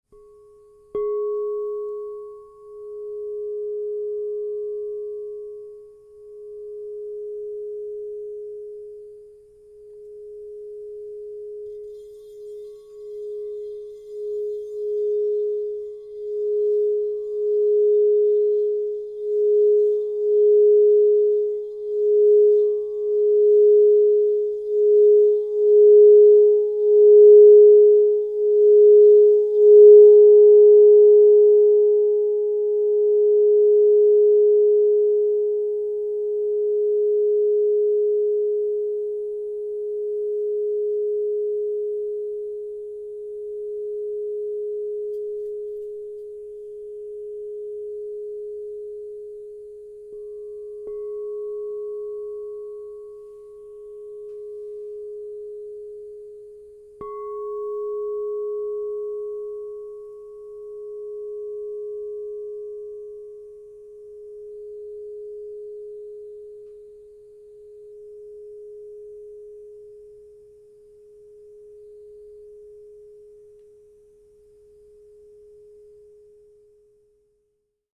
Mt. Shasta Serpentine, Sedona Red Rock 7" G# +10 Crystal Tones Singing Bowl
Genuine Crystal Tones® Alchemy Singing Bowl.
+10 (True Tone)
440Hz (TrueTone), 528Hz (+)
G#